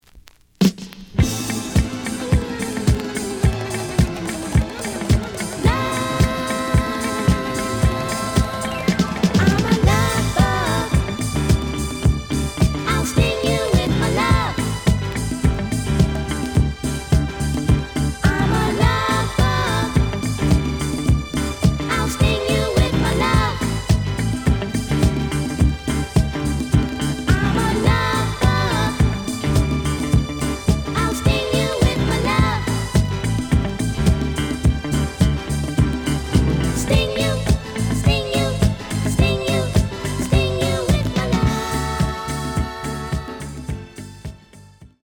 The audio sample is recorded from the actual item.
●Format: 7 inch
●Genre: Disco